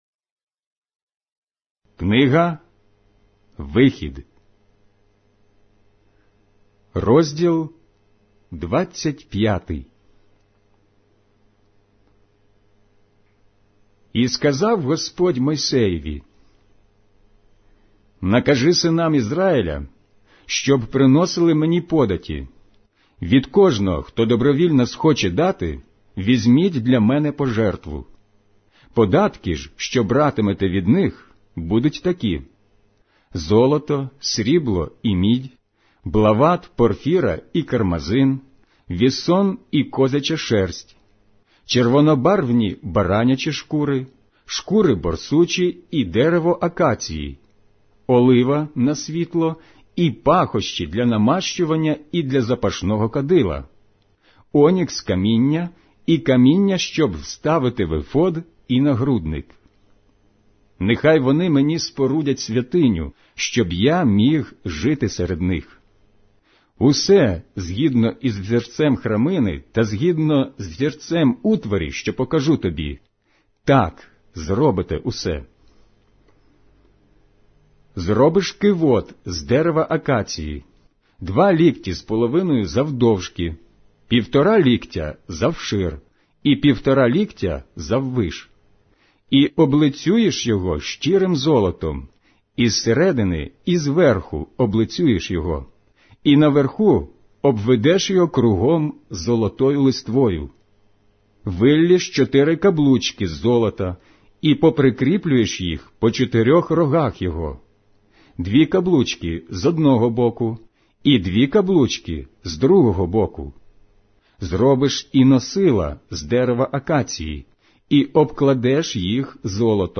OT/NT Drama